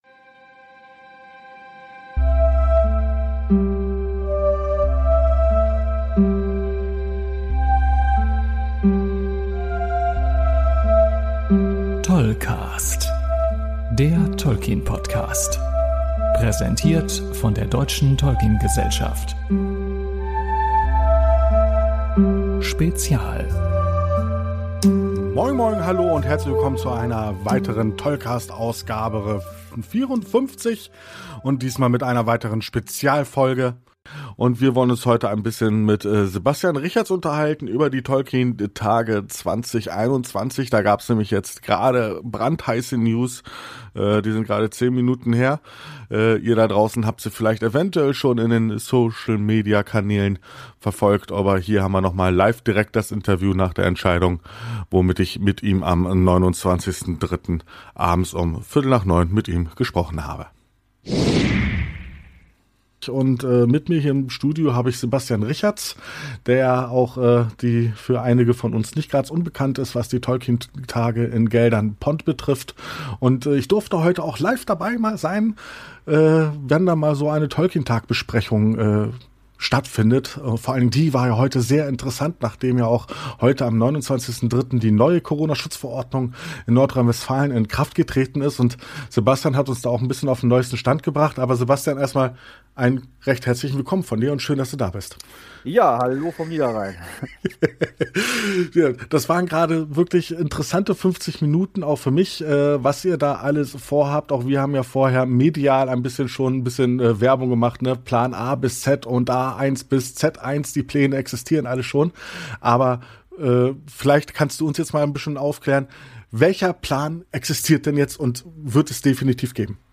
Aus einem geplanten 10-minütigem Interview wurden ratz fatz über 30 Minuten.